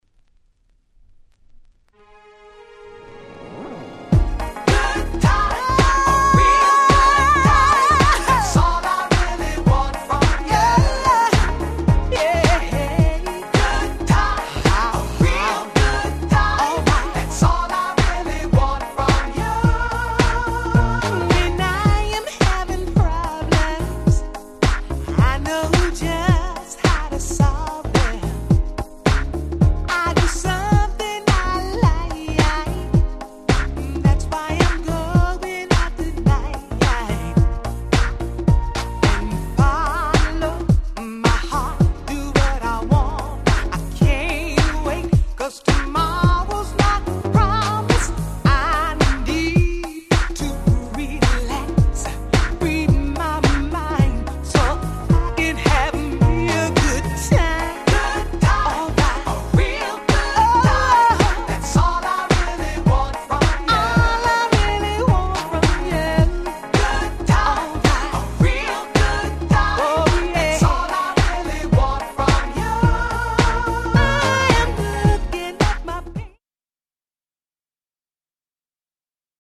その音と来たらまさに当時で言えば『現代版New Jack Swing』と言った感じでした。